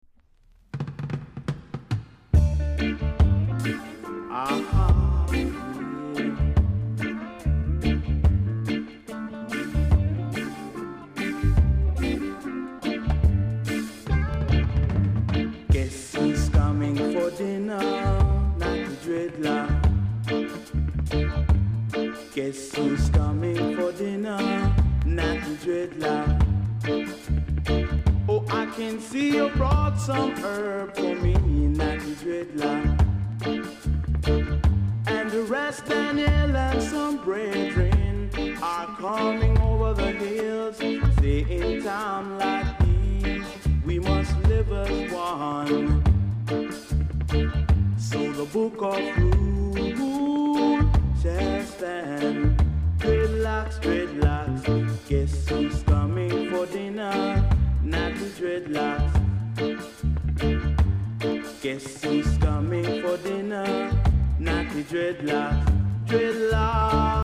※小さなチリノイズが少しあります。
コメント ROOTS CLASSIC!!RARE 1st CUT!!